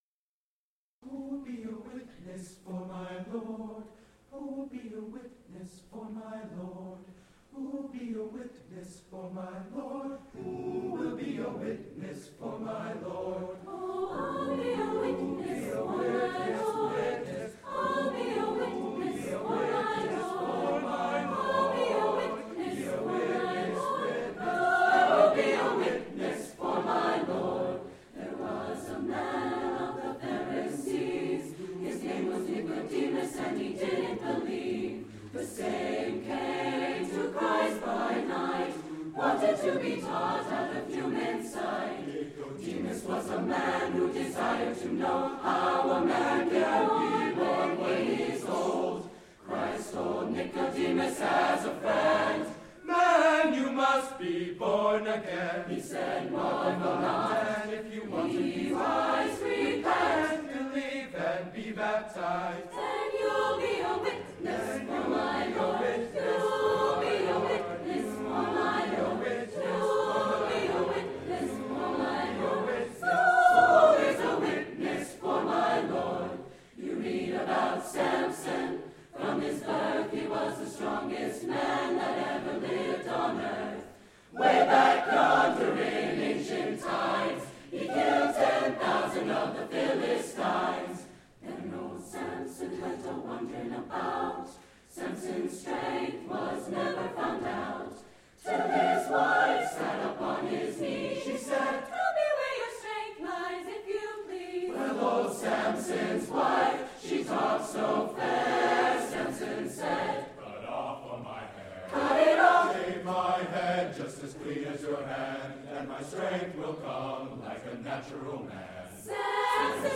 Wednesday, November 12, 2010 • Roberts-Dubbs Auditorium, Brookline High School
Camerata
“Witness” – African American Spiritual, arr. Jack Halloran